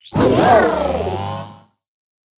Cri de Miraidon Mode Monture dans Pokémon Écarlate et Violet.